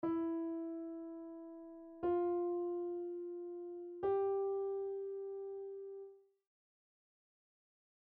Piano Notes
efg.mp3